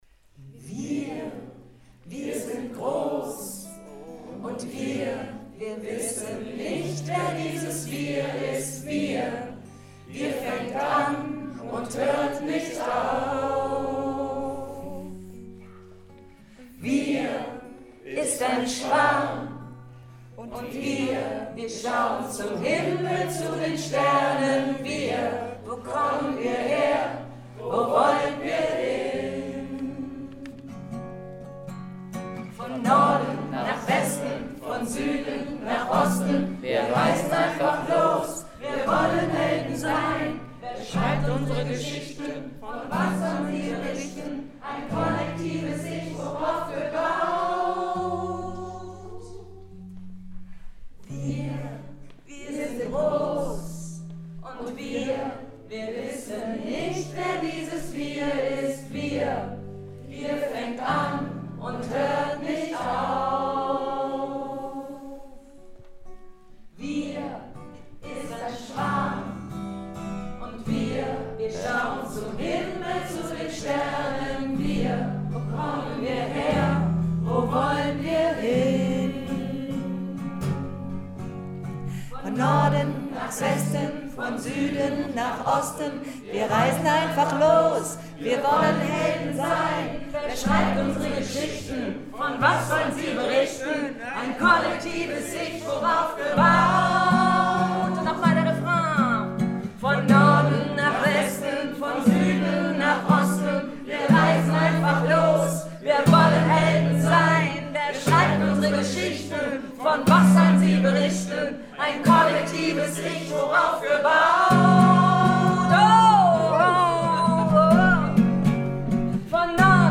Chor
Chormaterialien
Komposition: Masha Qrella Text: Gesine Danckwart und Masha Qrella